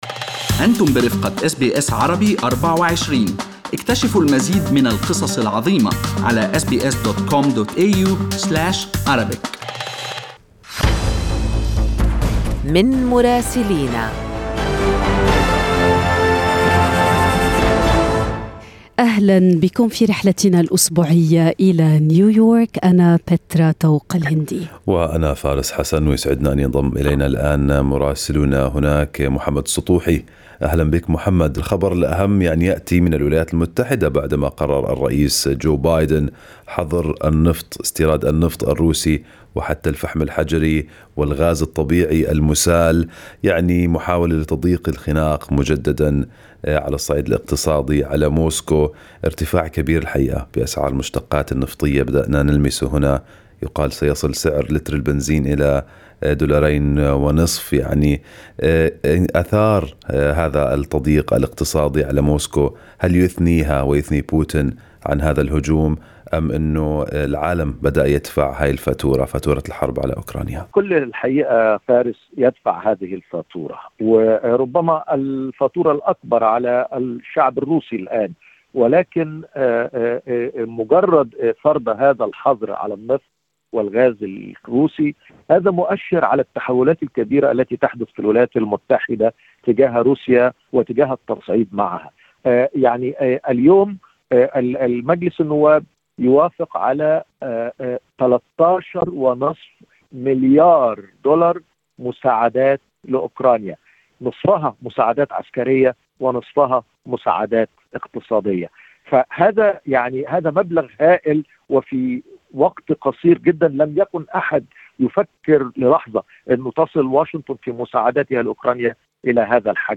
من مراسلينا: أخبار الولايات المتحدة الأمريكية في أسبوع 10/3/2022